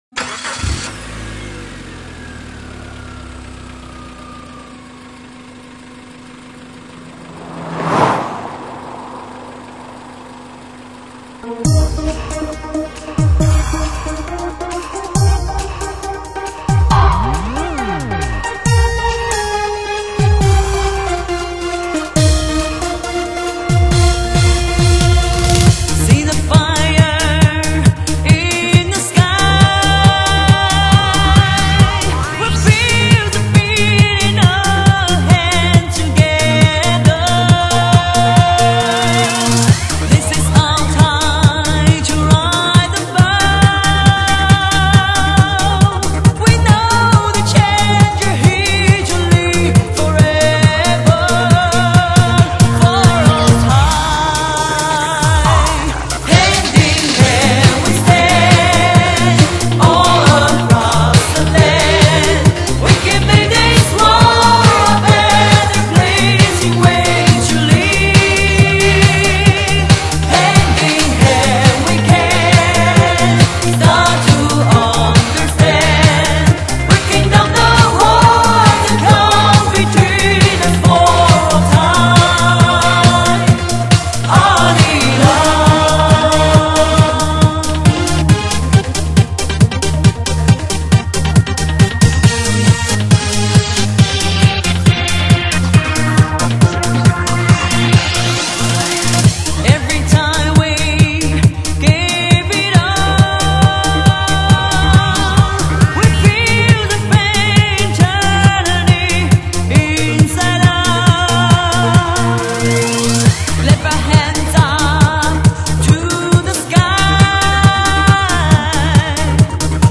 创新重装劲爆串嗨